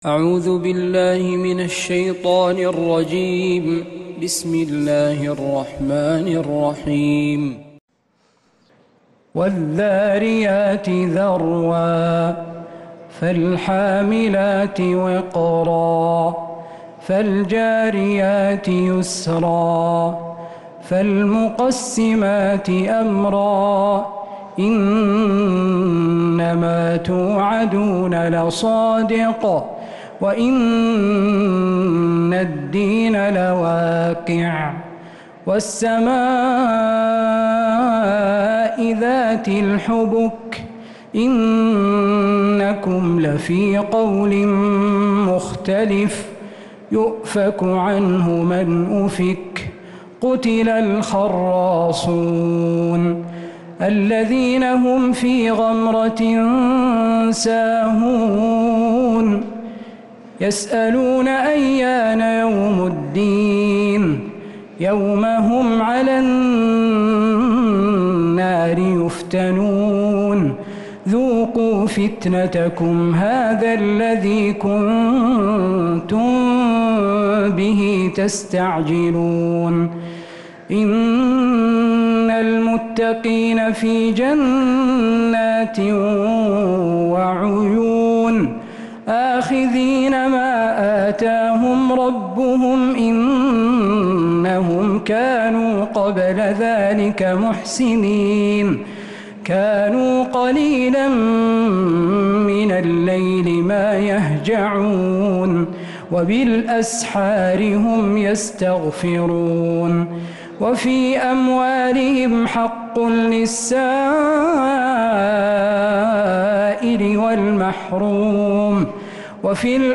سورة الذاريات من تراويح الحرم النبوي